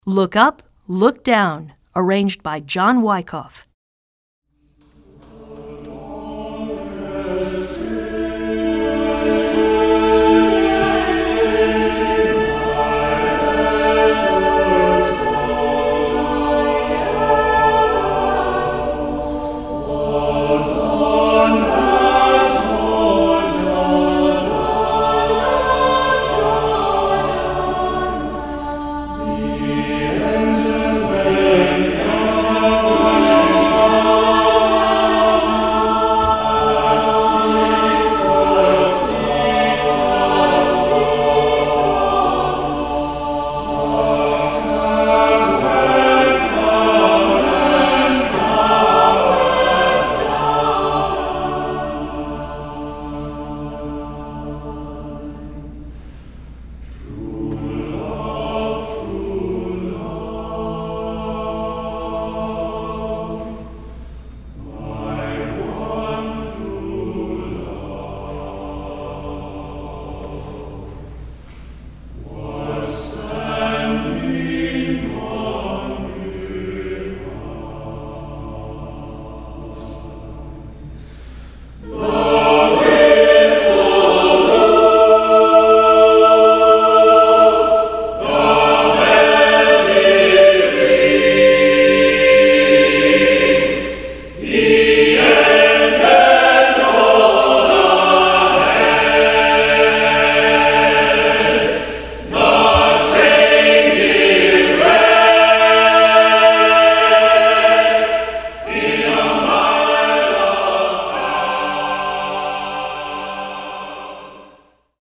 Choeur Mixte (SATB)